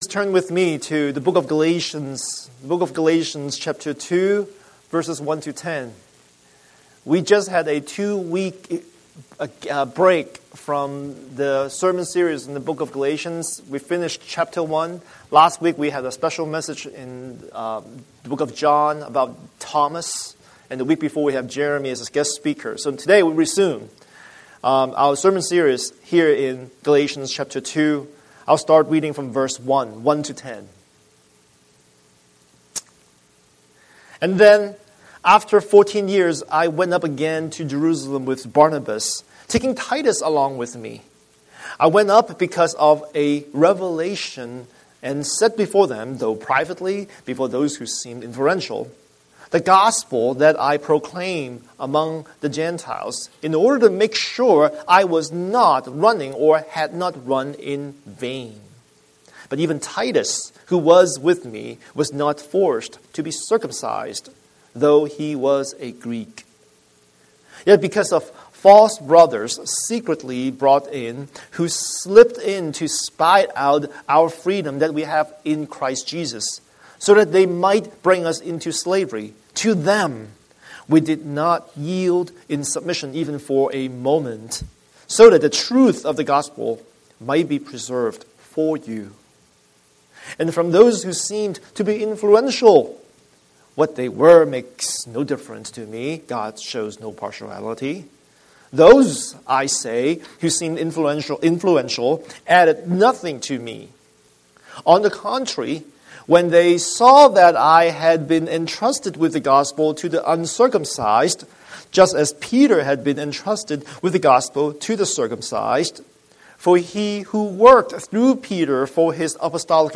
Scripture: Galatians 2:1–10 Series: Sunday Sermon